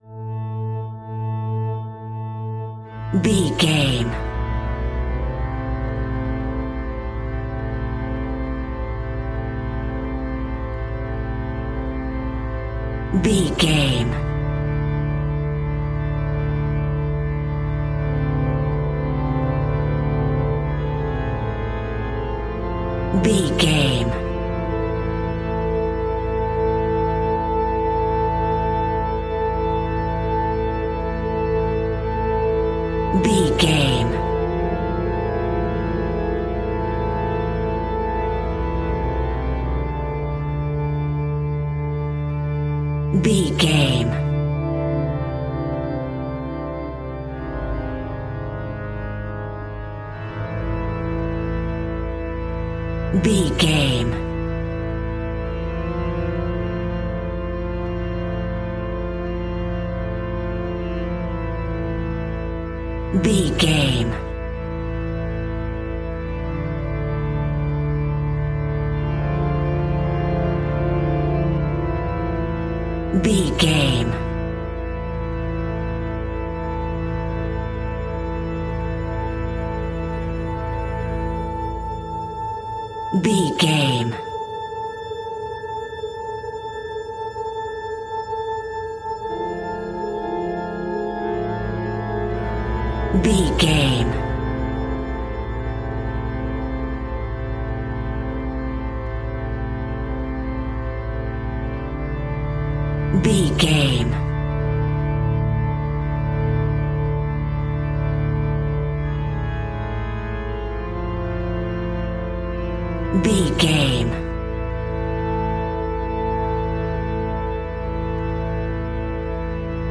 Aeolian/Minor
Slow
scary
ominous
dark
suspense
eerie
organ
horror
cymbals
gongs
viola
french horn trumpet
taiko drums
timpani